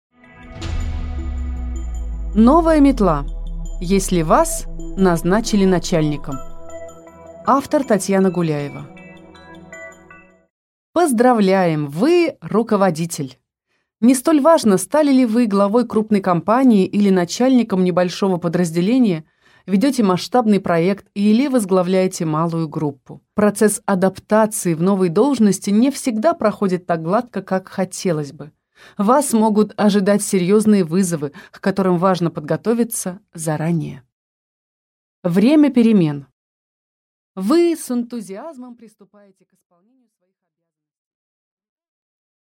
Аудиокнига Новая метла | Библиотека аудиокниг